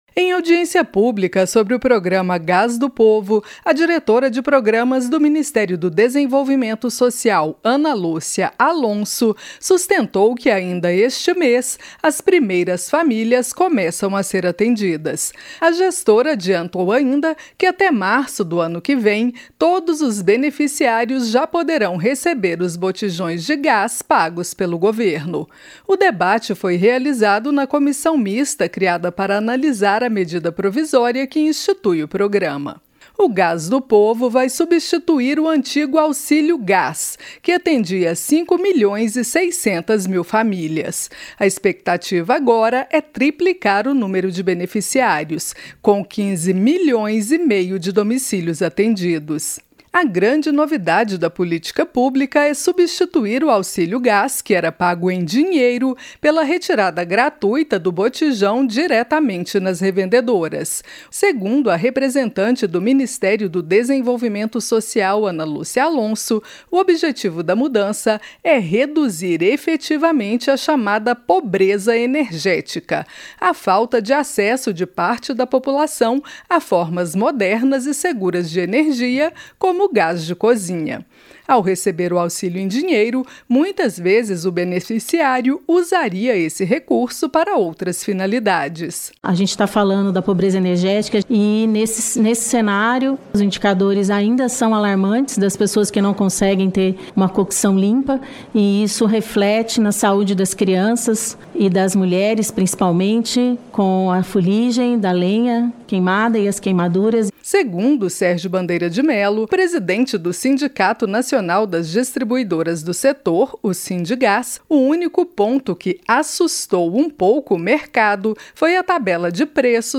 Parceria Rádio Câmara e Rádio Senado